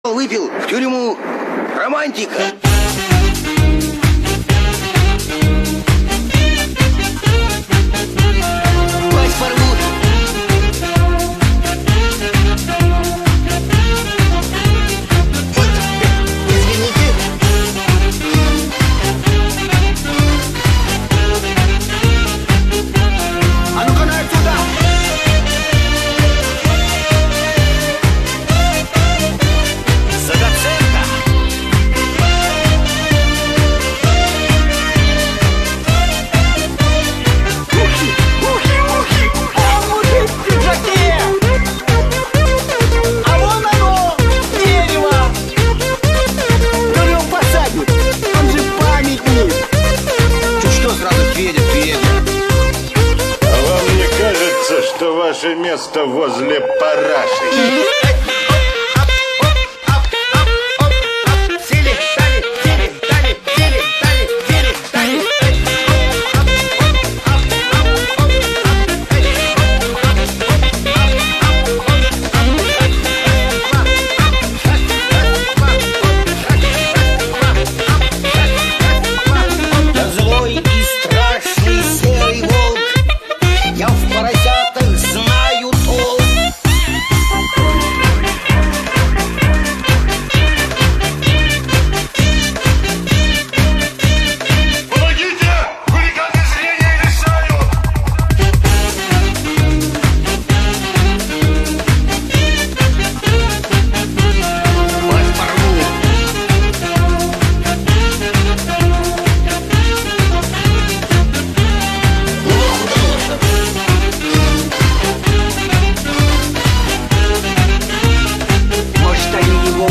• Качество: 128, Stereo
голосовые
крылатые фразы
с юмором